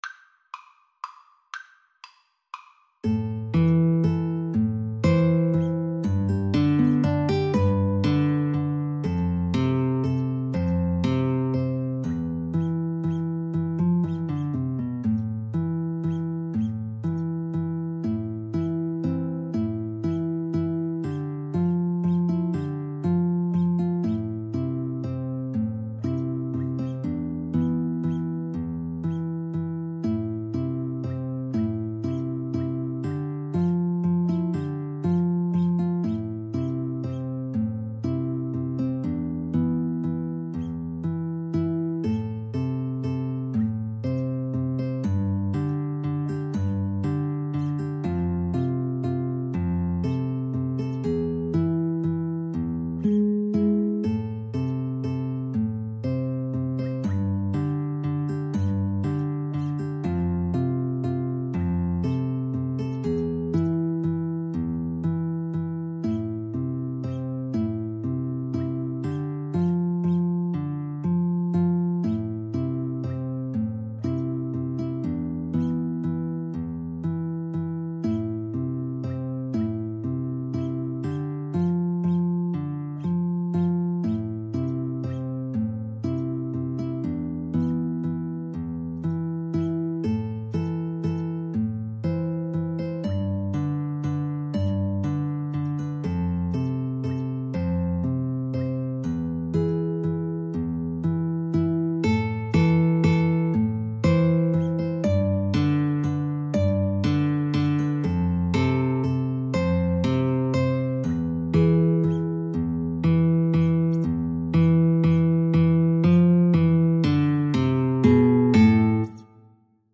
3/4 (View more 3/4 Music)
A minor (Sounding Pitch) (View more A minor Music for Guitar Trio )
Slow Waltz .=40
Traditional (View more Traditional Guitar Trio Music)